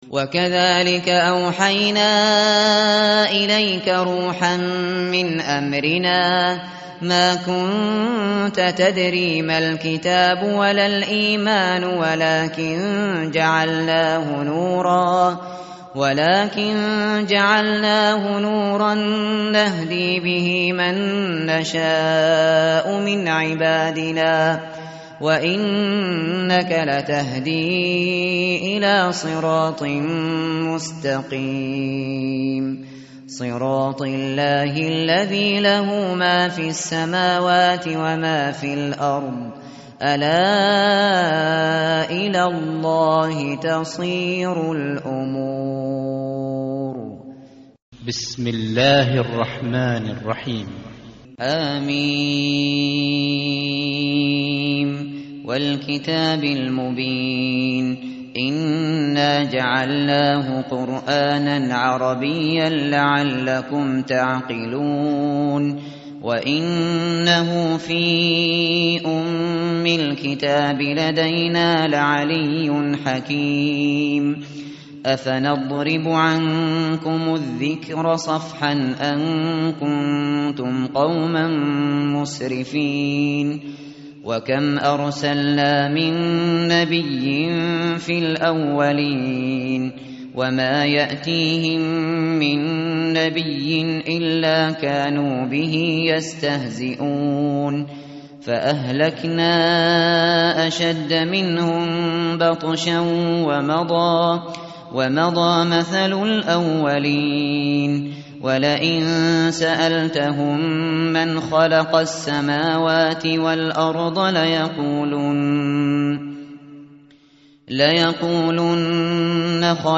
tartil_shateri_page_489.mp3